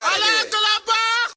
Screaming Busta Scream 2